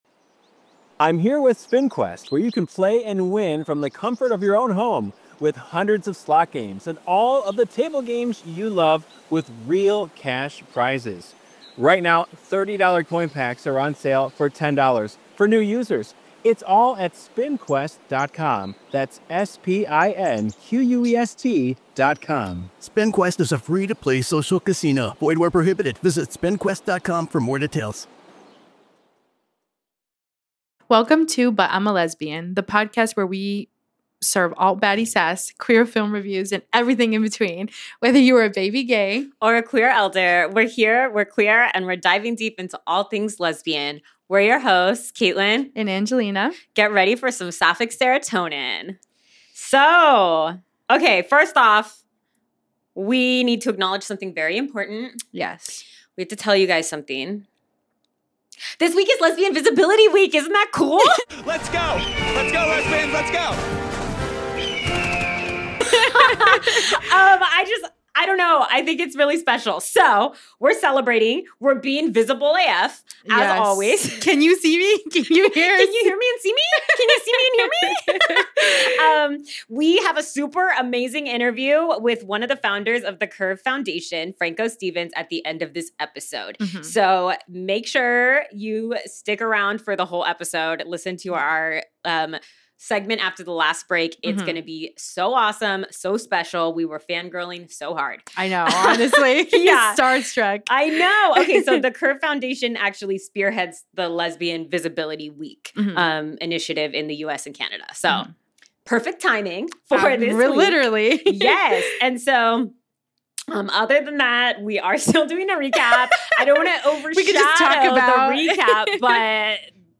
🎙 But I’m a Lesbian Two lesbians. Two mics. Endless hot takes.